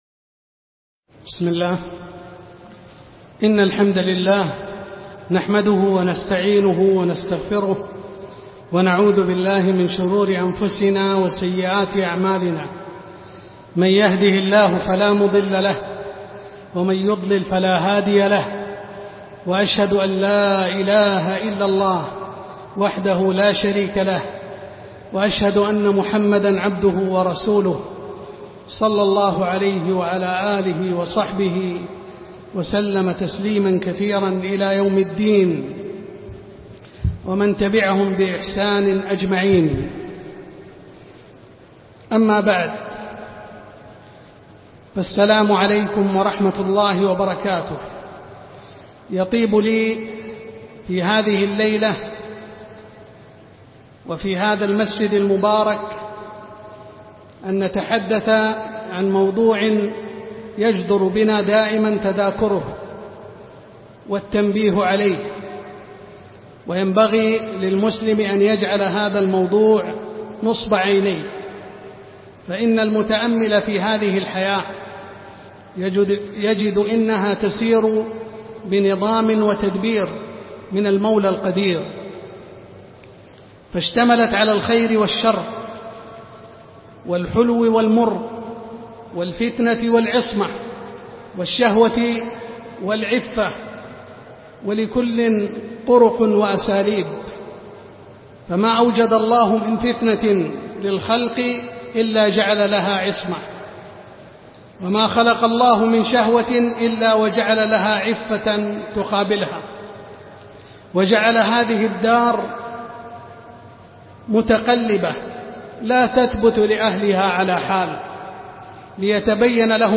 محاضرة - زيارة القبور وأحكامها